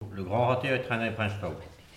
RADdO - Le râteau mécanique est traîné par un cheval - Document n°215412 - Locution
Elle provient de Saint-Gervais.